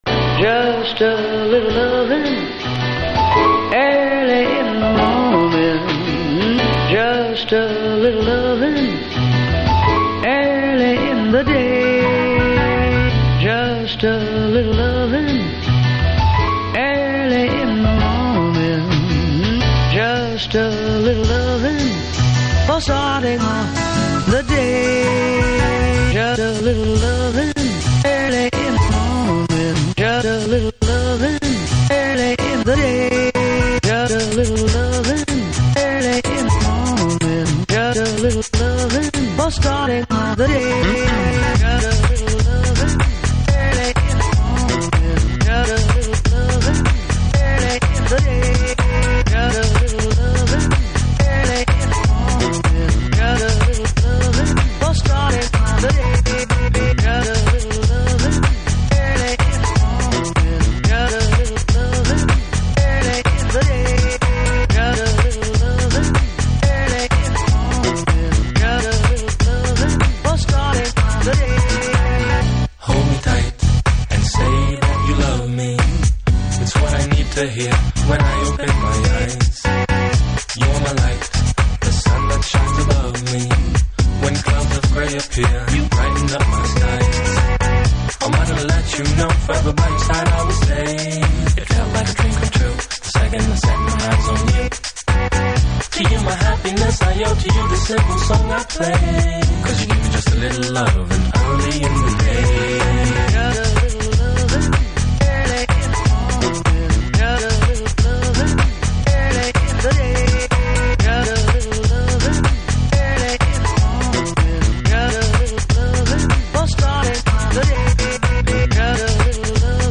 Future Jazz / Broken beats